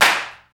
35 CLAP   -R.wav